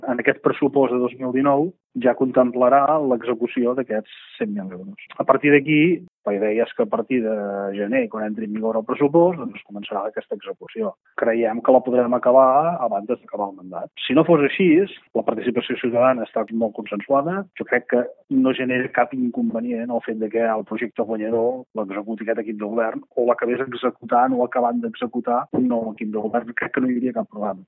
Ho explica a Ràdio Capital, Marc Calvet, regidor de participació ciutadana de Torroella de Montgrí.
El regidor de participació ciutadana, Marc Calvet, a aquesta emissora.